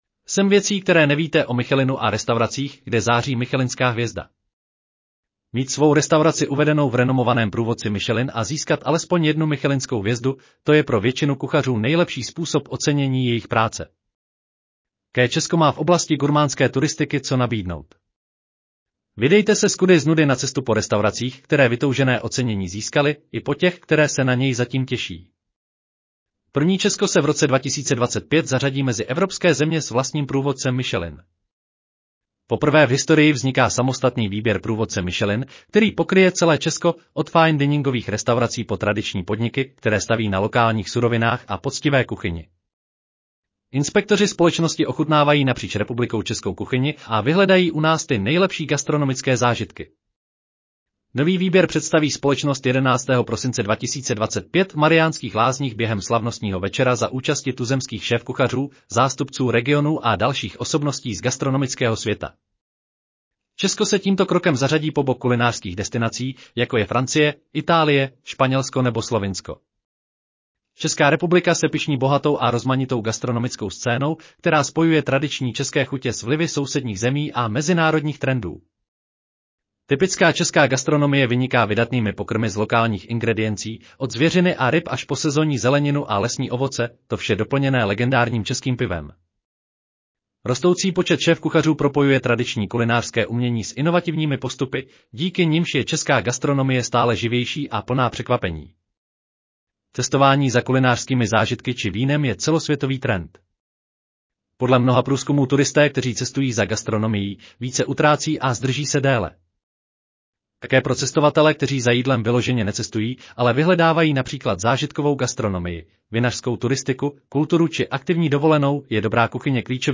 Audio verze článku 7 věcí, které nevíte… o michelinských hvězdičkách a oceněných restauracích